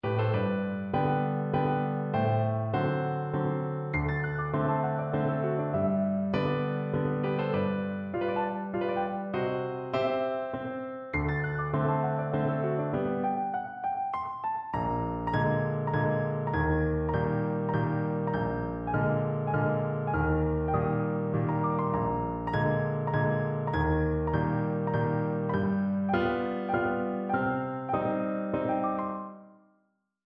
MIDIBigot, F. (unknown composer) Violette for piano, Polka Mazurka, moderato, mm.49-62